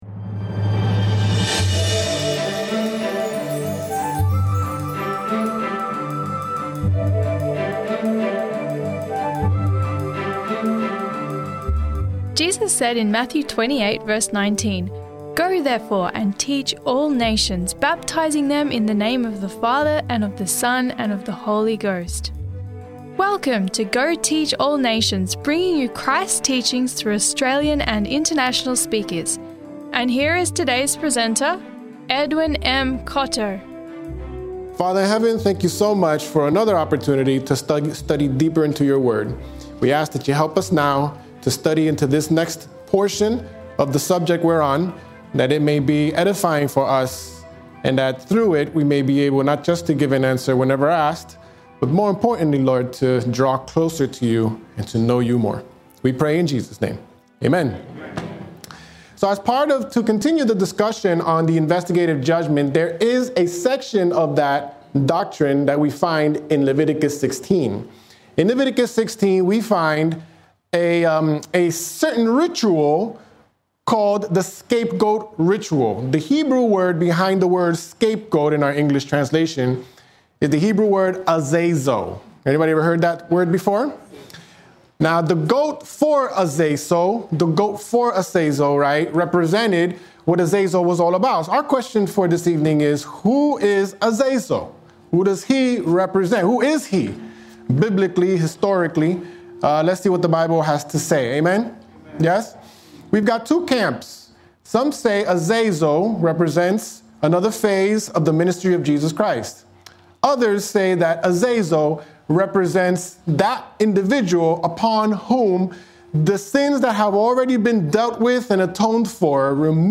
Unveiling the Scapegoat: Exploring the Investigative Judgment – Sermon Audio 2605